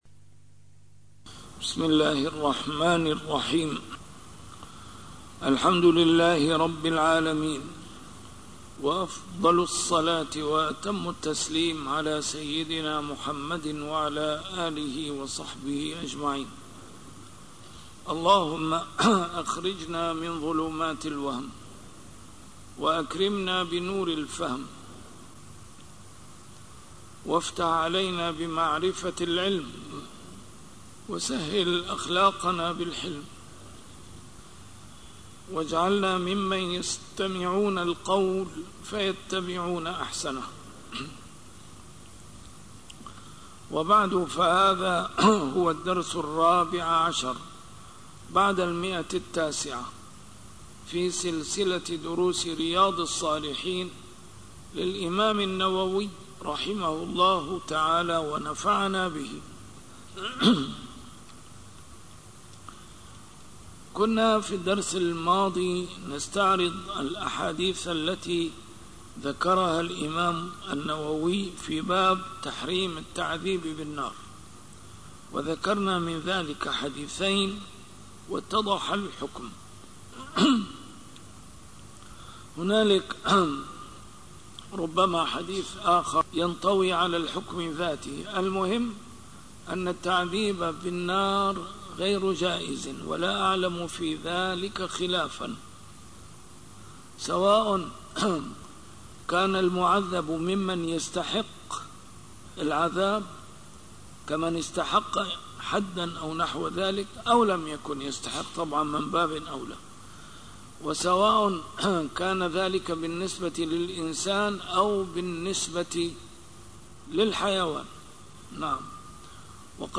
A MARTYR SCHOLAR: IMAM MUHAMMAD SAEED RAMADAN AL-BOUTI - الدروس العلمية - شرح كتاب رياض الصالحين - 914- شرح رياض الصالحين: تحريم مطل الغني